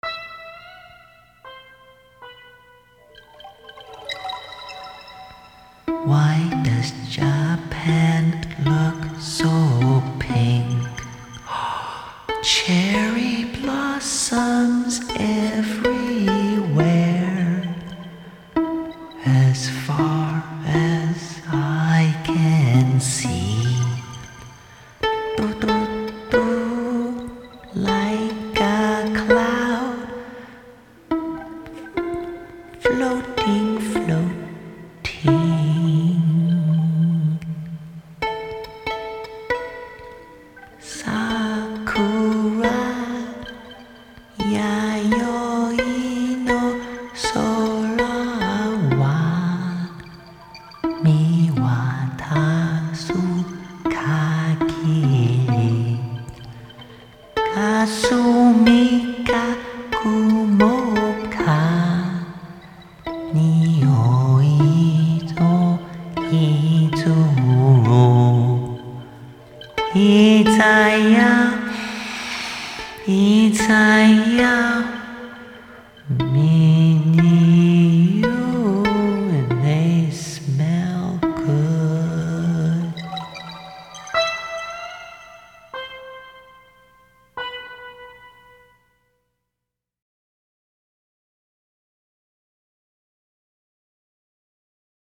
this is a great stoner song to help them on their trip!